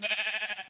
animalia_sheep_idle.ogg